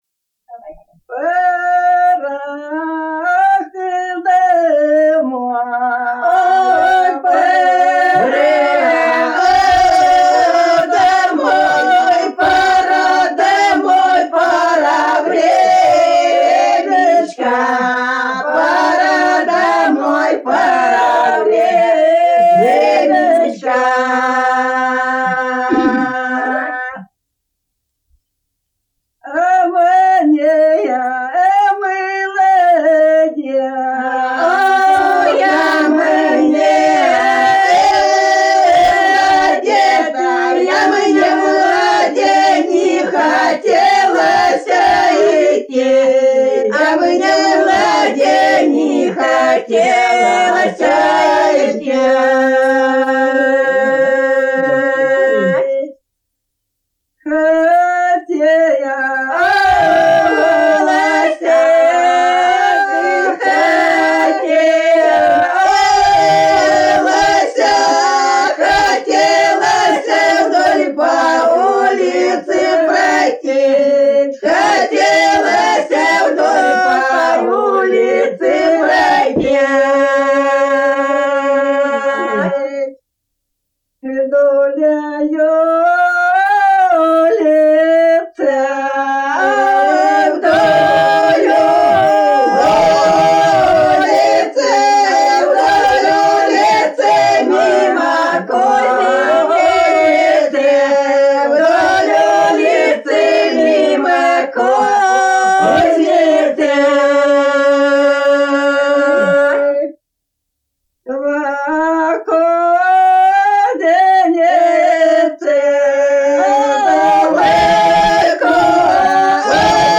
Народные песни Касимовского района Рязанской области «Пора, ох, домой», плясовая.